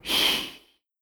WindMove.wav